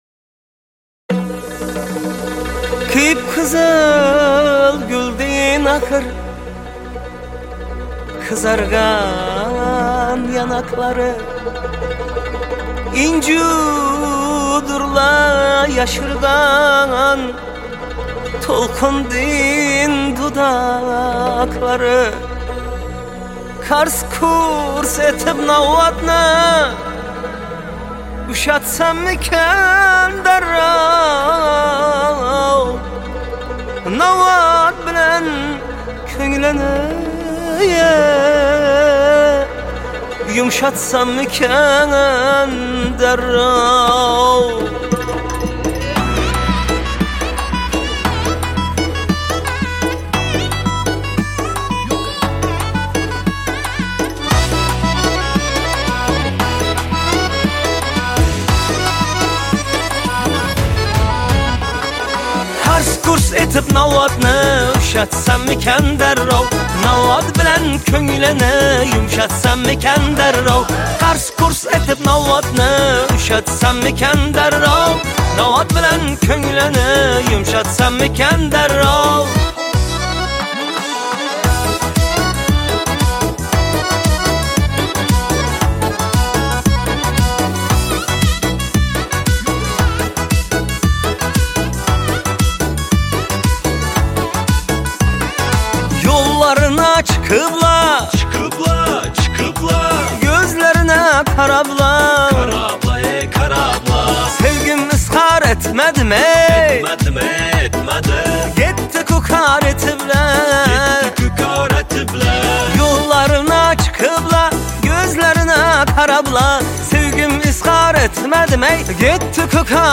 Жанр: Узбекская музыка